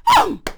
cudgel_attack4.wav